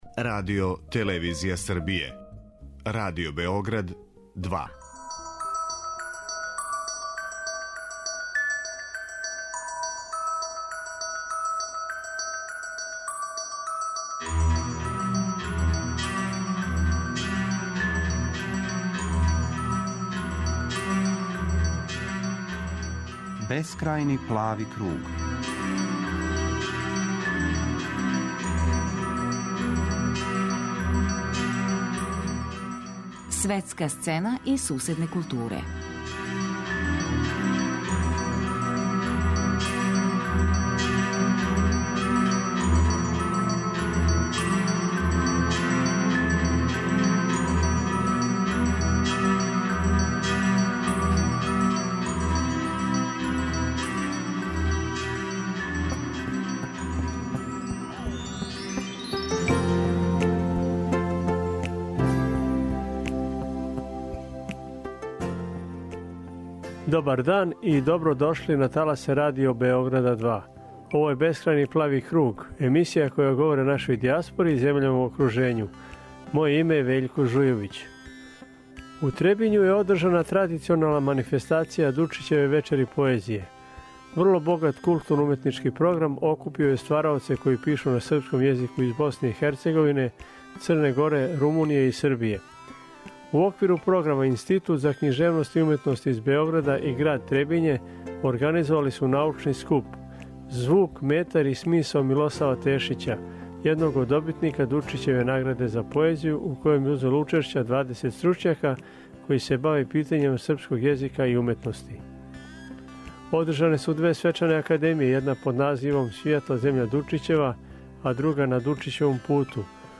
У данашњој емисији чућемо неке од учесника 'Дучићевих вечери поезије' и пренети део атмосфере из Требиња.